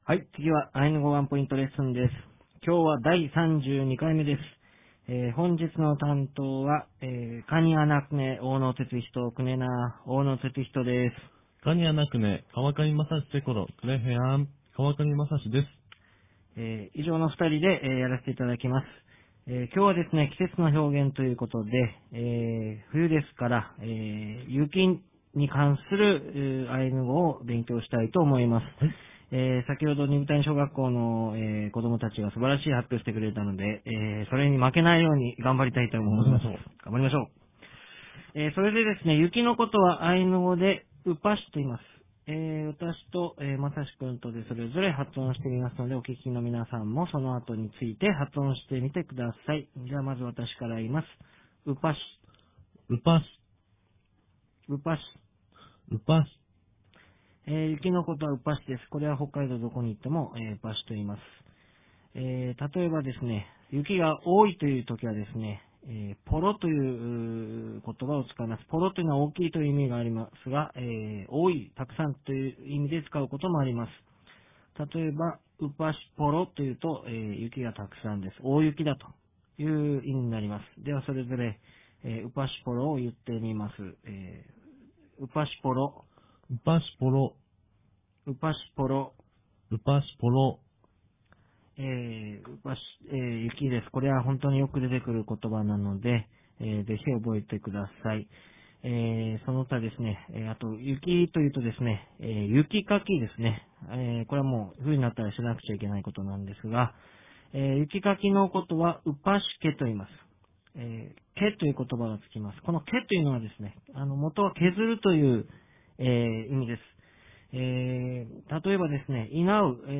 アイヌ語ワンポイントレッスン